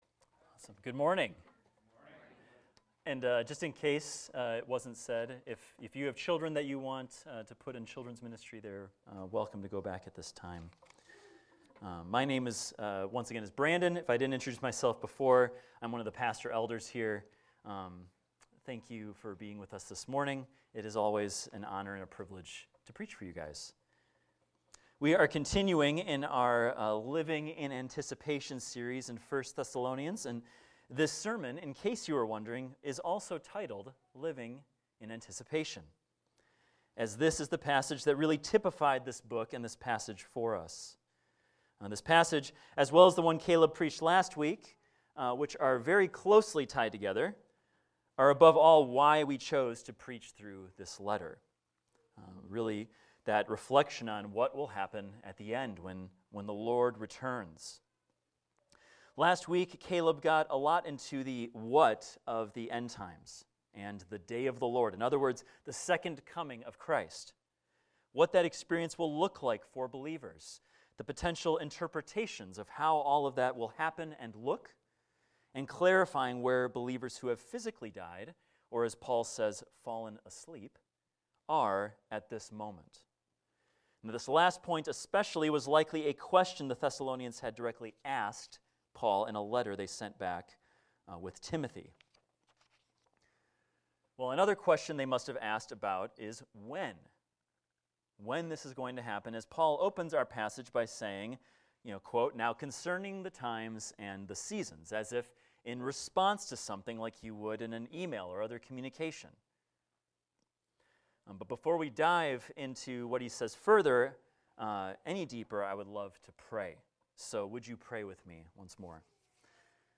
This is a recording of a sermon titled, "Living in Anticipation."